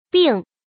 “枋”读音
bìng
国际音标：fɑŋ˥;/piŋ˥˧
bìng.mp3